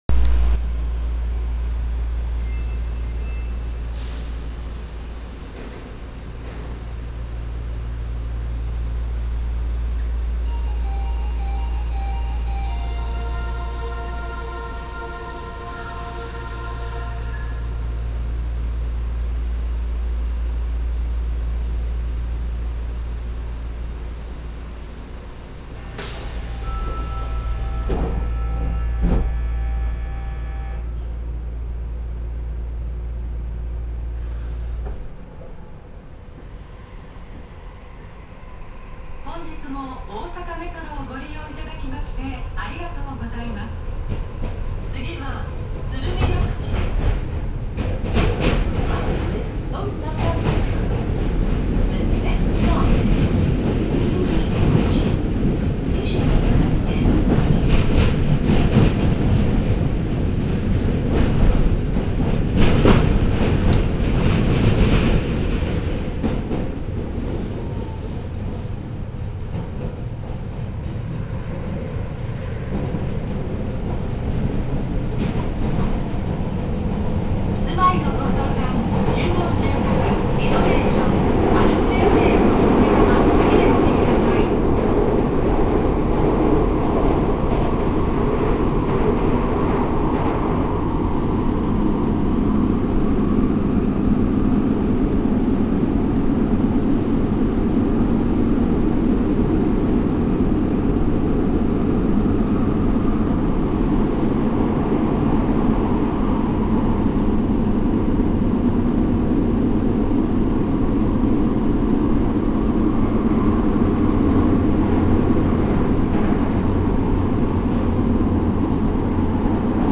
・70系更新車走行音
長堀鶴見緑地線：門真南→鶴見緑地（2分37秒：856KB）
日立IGBTに変わりましたが、やはりリニアモーター故に音の聞こえ方は特徴的。更新を受けてもドアチャイムにはならずドアブザーを使用し続けているようです。